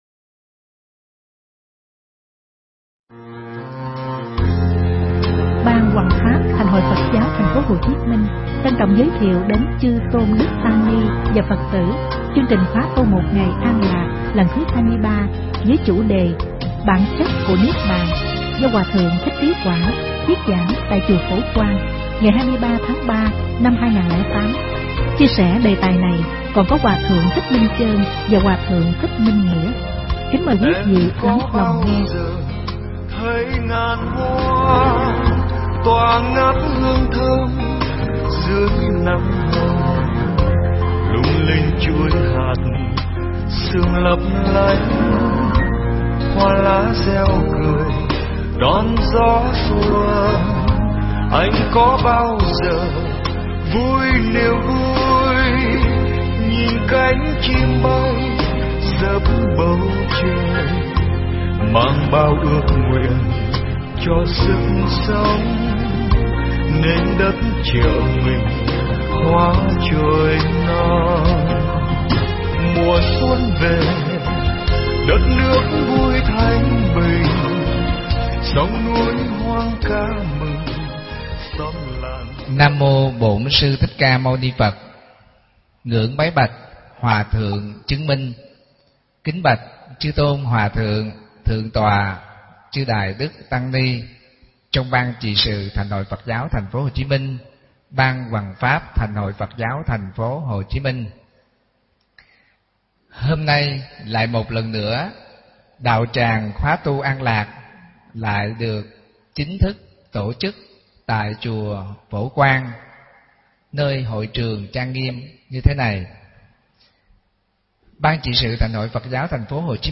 Mp3 Pháp Thoại Bản Chất Của Niết Bàn – Hòa Thượng Thích Trí Quảng giảng trong Khóa Tu Một Ngày An Lạc Lần Thứ 23, ngày 23 tháng 3 năm 2008